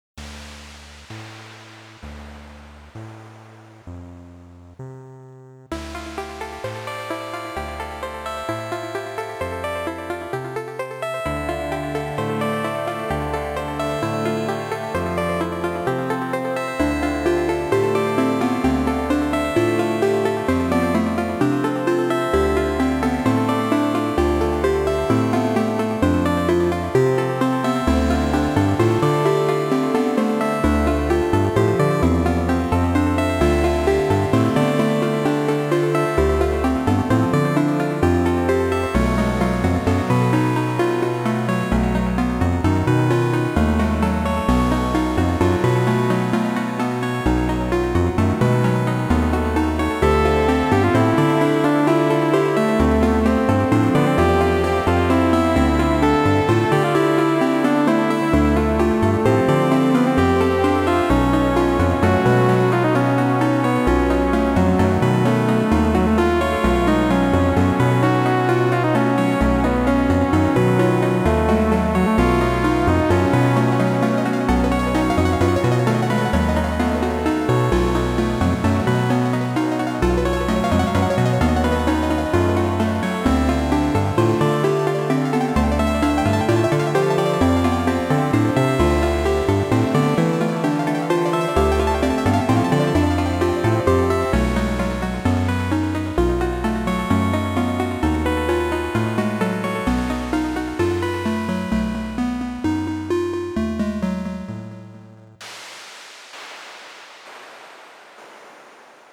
Ambient electronic sound